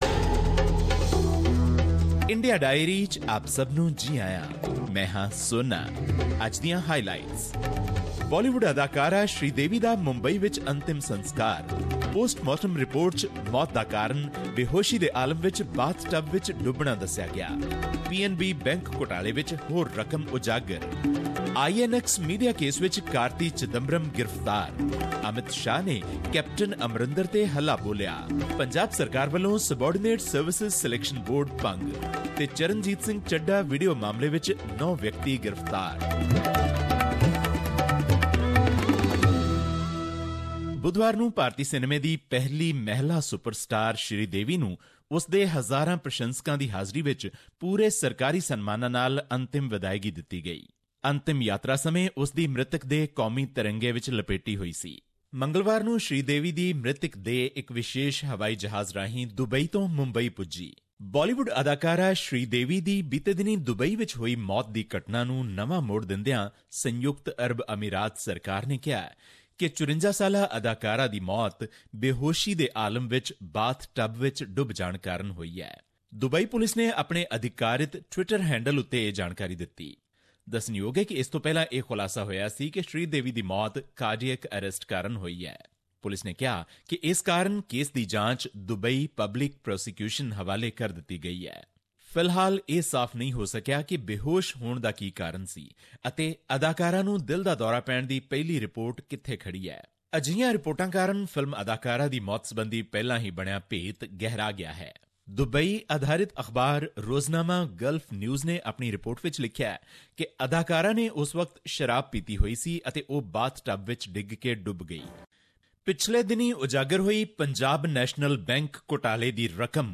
In our weekly India Diary, the leading news story is about the funeral of actress Sridevi, who will be remembered as Indian film industry's first female megastar.